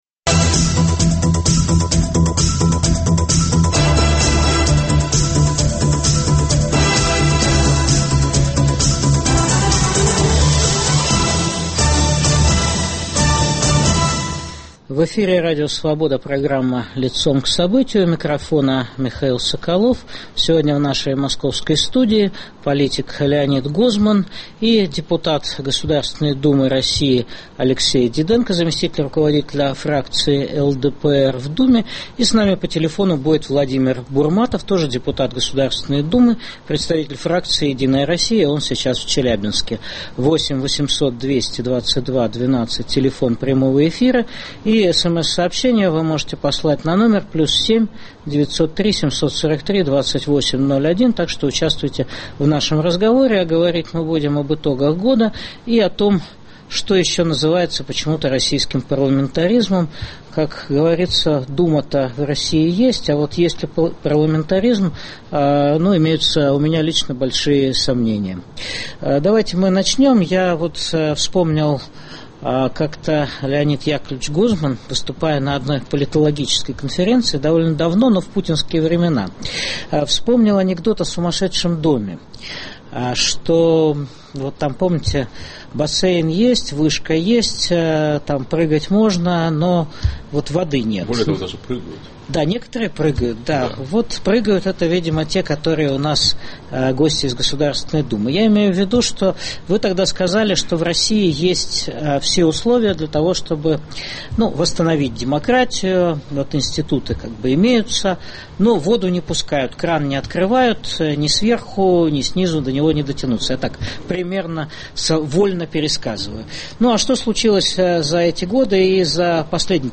В России есть Госдума, но нет парламента? О деградации институтов власти путинской России дискутируют политик Леонид Гозман и депутаты Госдумы России Алексей Диденко (ЛДПР) и Алексей Бурматов ("Единая Россия").